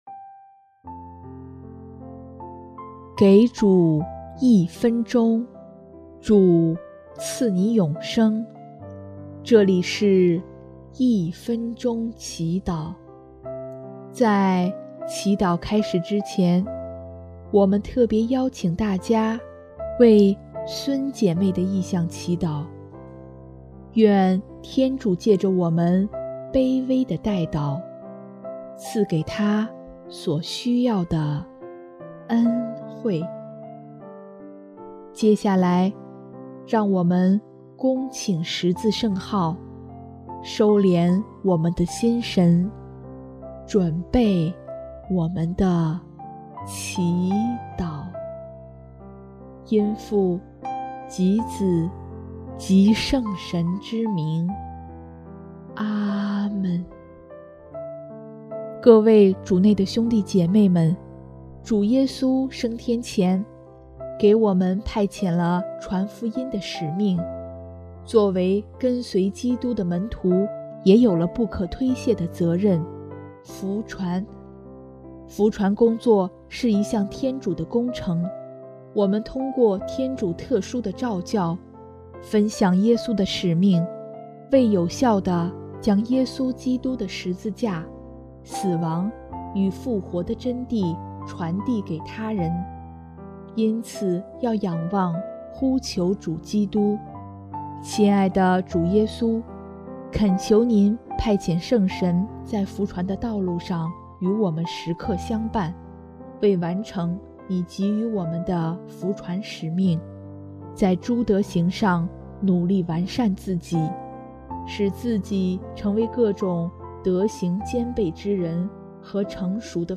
【一分钟祈祷】|5月9日 福传使命的召唤